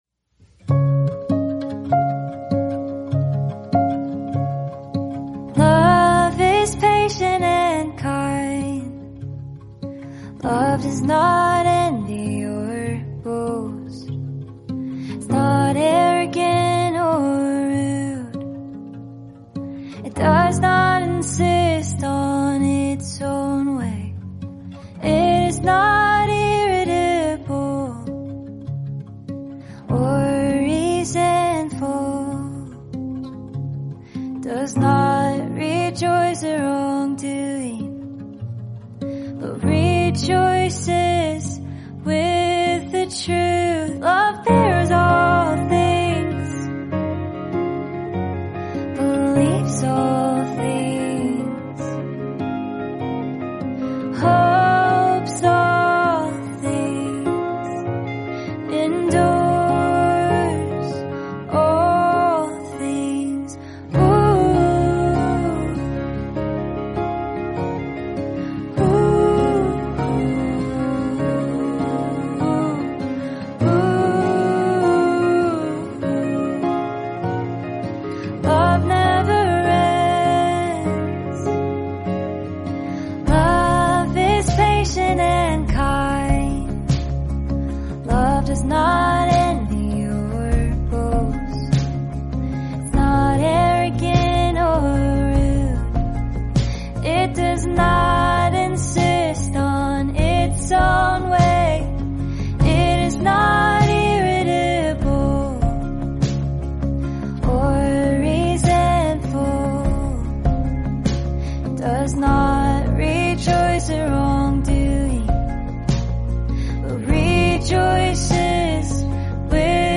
word-for-word Scripture song